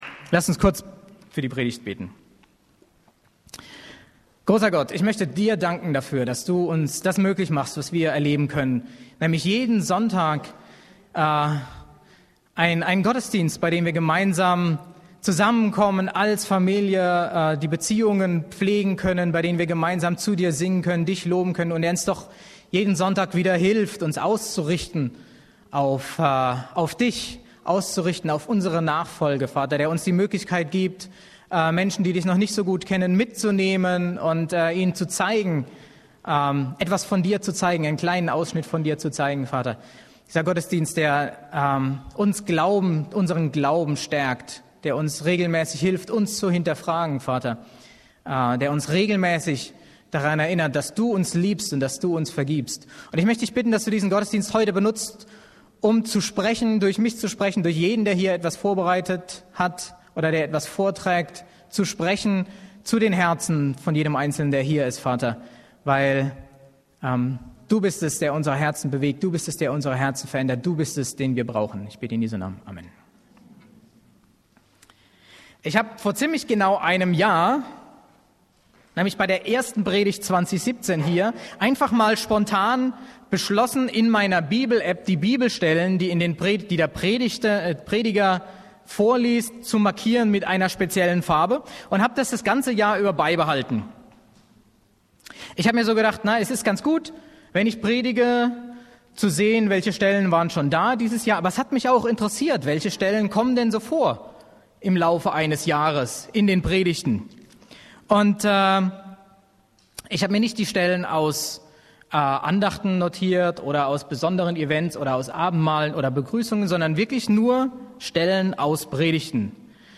Predigten - Berliner Gemeinde Christi